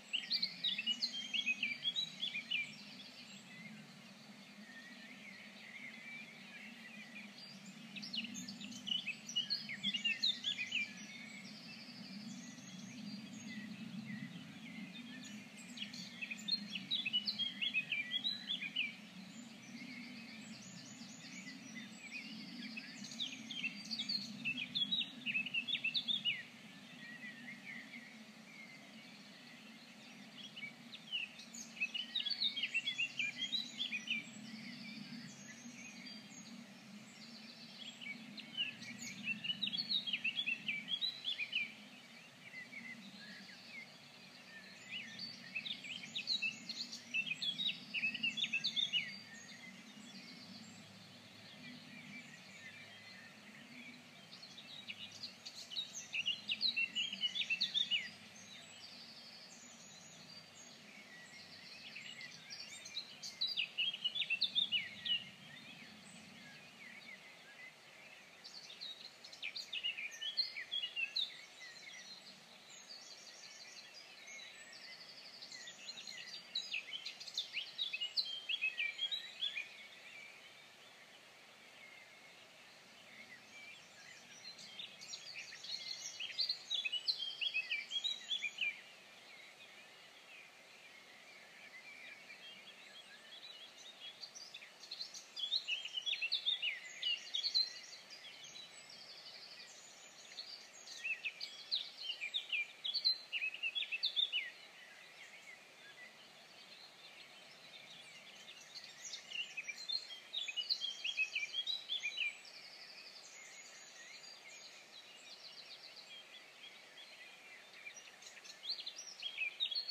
your meditation today
Lovely to hear your birdsong and ours through my open window competing before I start my practice!